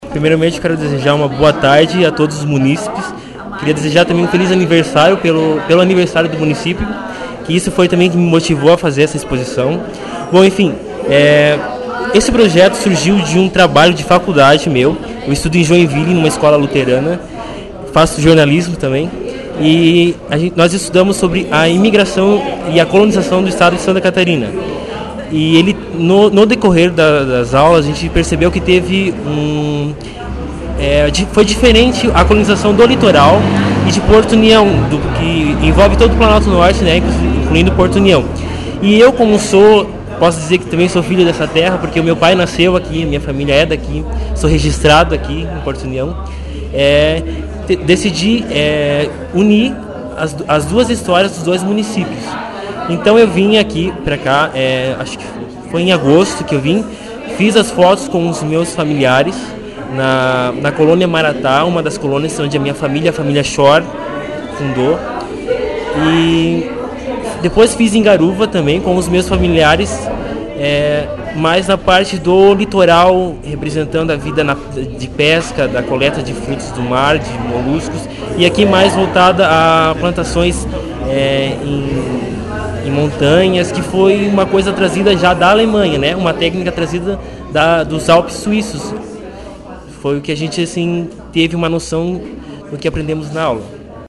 ao conversar com o jornalismo da Rádio Colmeia, comentou a sua ideia de fazer as fotos e conta um pouco de sua vida na fotografia.